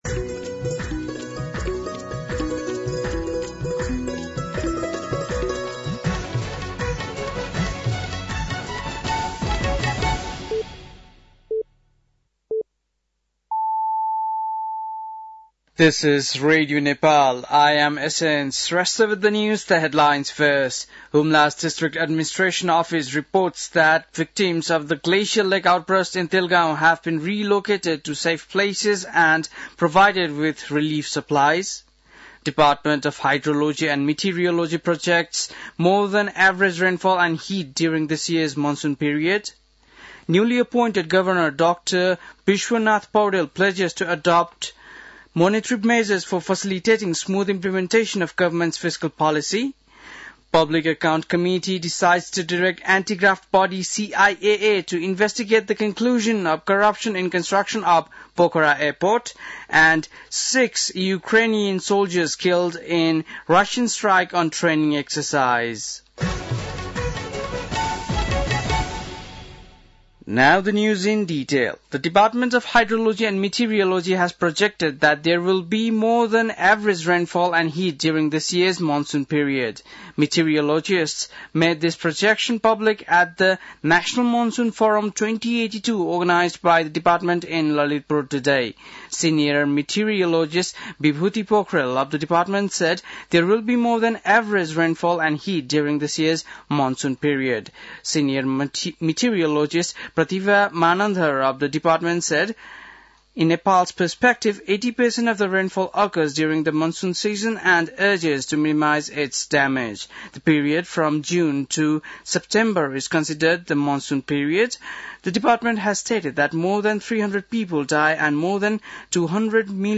बेलुकी ८ बजेको अङ्ग्रेजी समाचार : ७ जेठ , २०८२